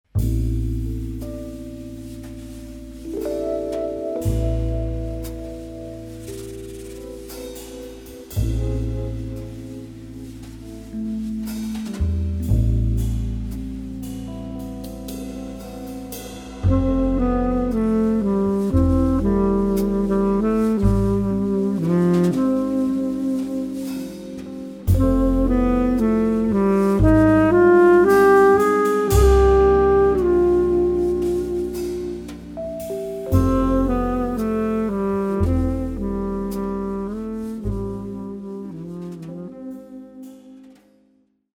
sax
guitar
Fender Rhodes
bass
drums